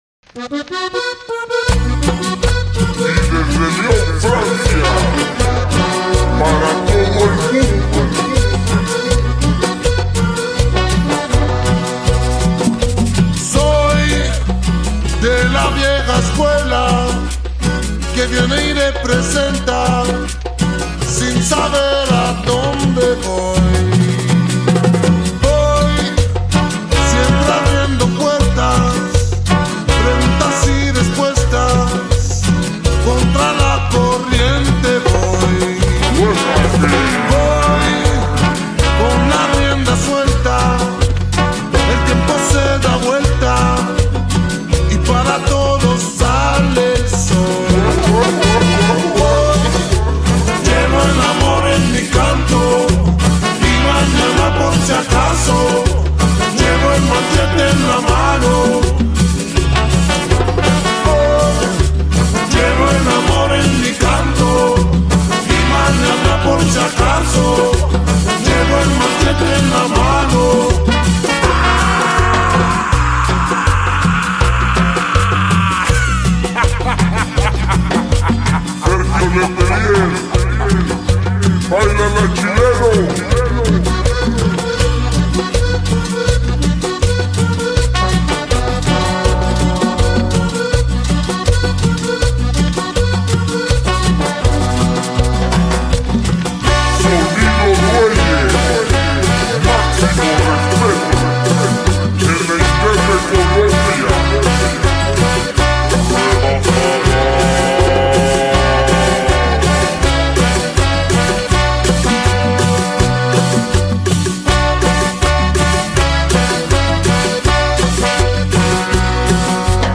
Intervista ai Kumbia Boruka – Rototom Sunsplash 2025 📻 Conduce
Artista-a-la-Vista-Intervista-Kumbia-Boruka.mp3